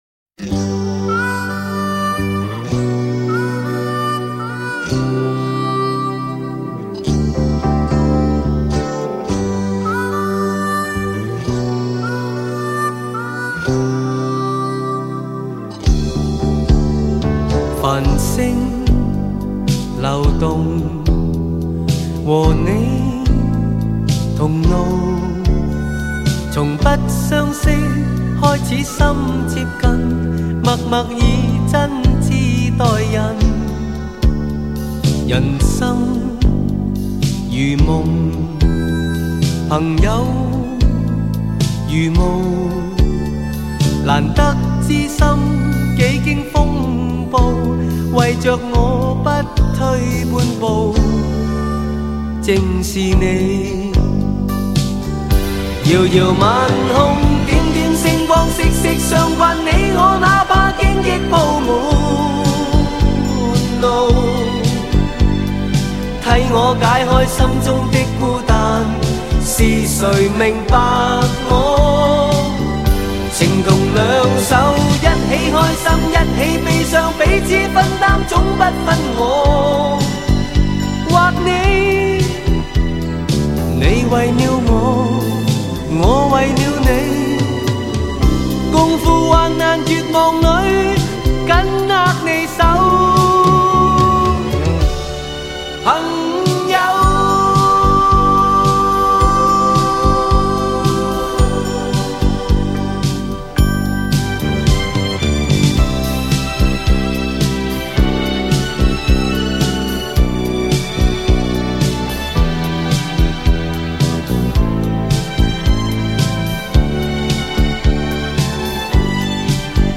专辑内所有歌曲之母带经由 PHILIPS SA-CD 泛亚数码中心 制作